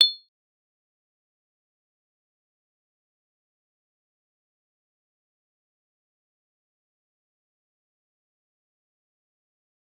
G_Kalimba-A7-f.wav